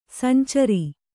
♪ sancari